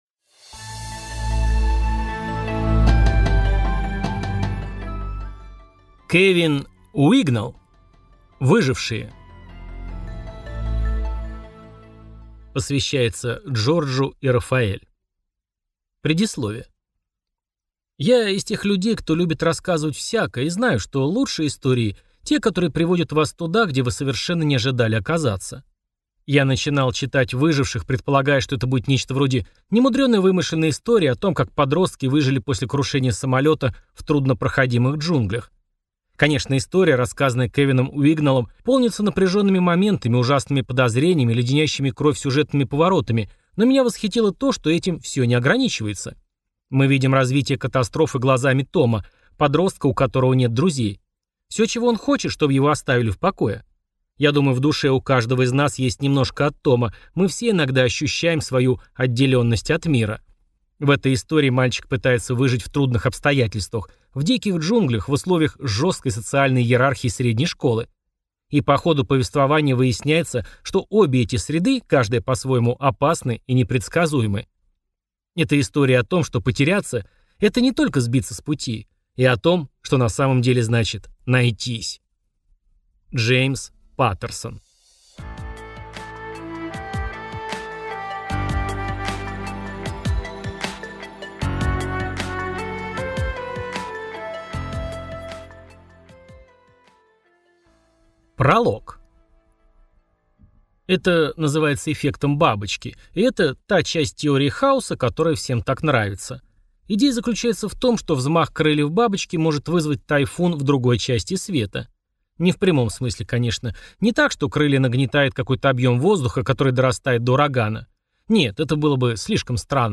Аудиокнига Выжившие | Библиотека аудиокниг